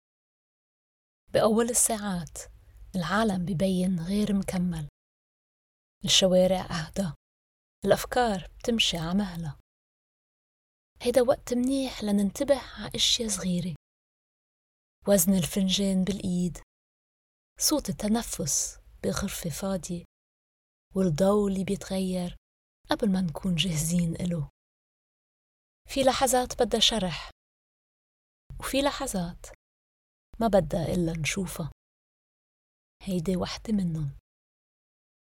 Levantine Arabic Sample
Levantine English, French and Arabic